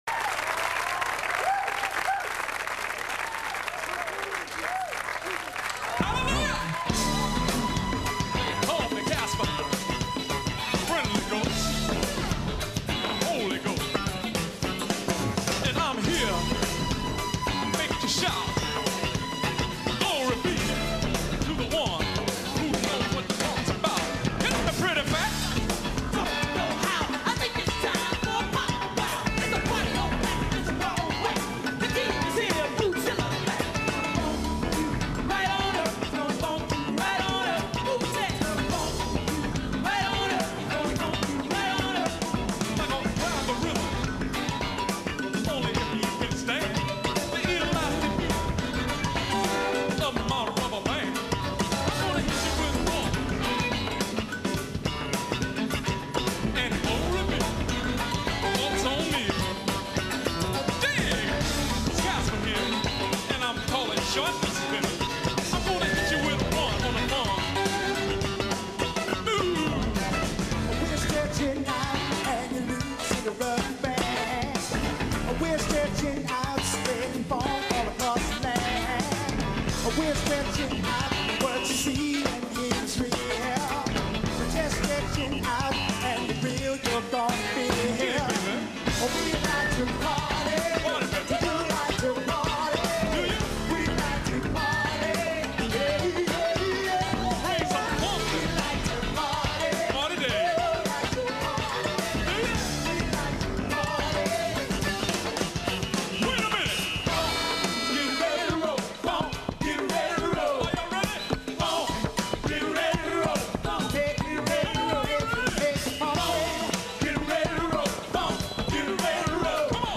P-Funk, années 70-80
Un nouveau genre plutôt psychédélique et délirant !